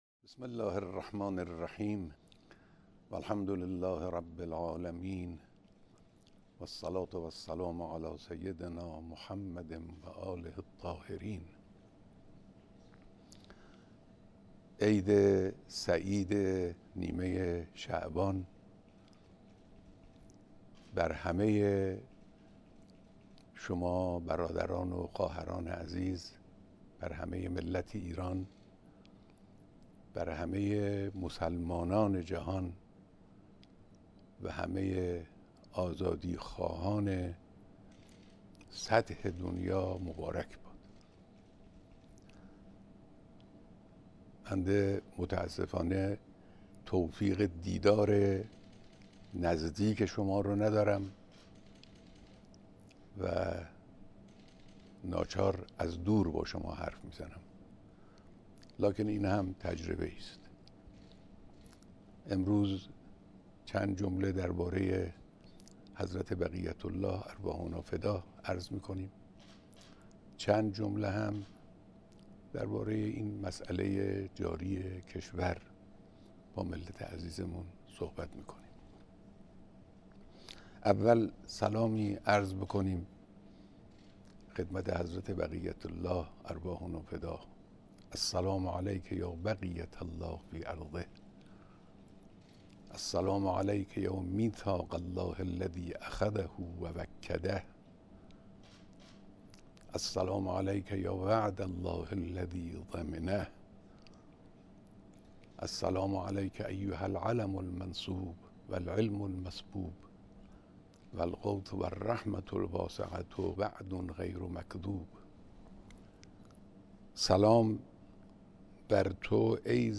سخنرانی زنده و تلویزیونی به مناسبت نیمه شعبان
بیانات به‌ مناسبت خجسته عید نیمه‌ شعبان